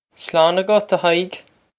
Pronunciation for how to say
Slawn a-gut, a Hi-ig!
This is an approximate phonetic pronunciation of the phrase.